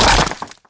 rifle_hit_stone2.wav